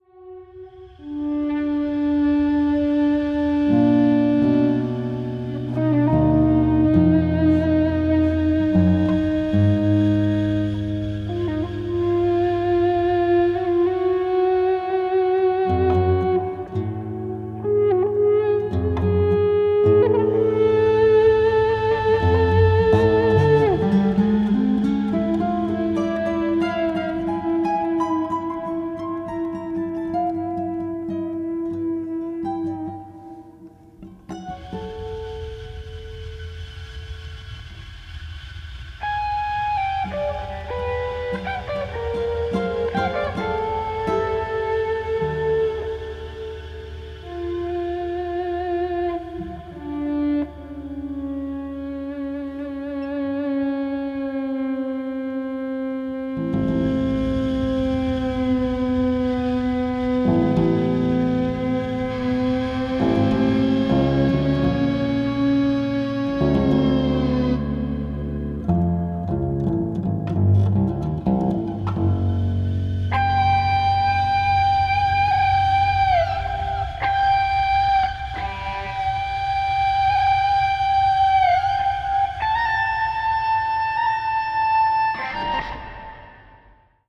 Electric guitar, Soundscapes, Live performance electronics